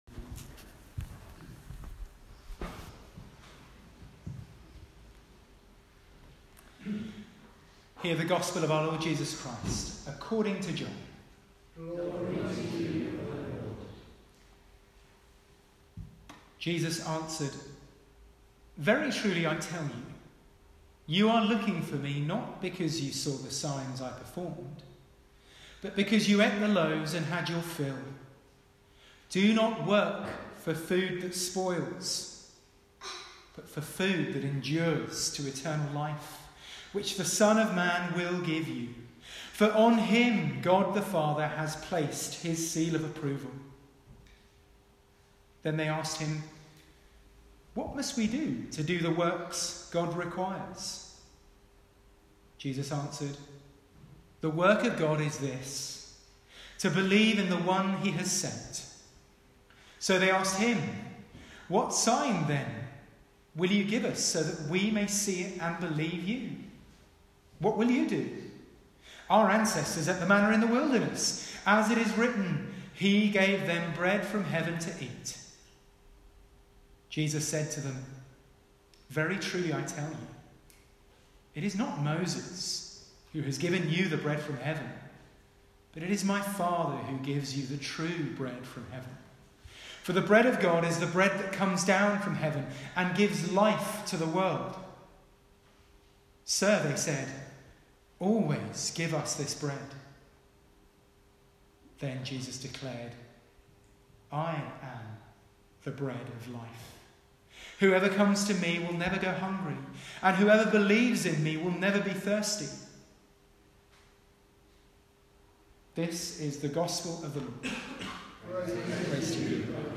Sermons | Denton Church | Page 8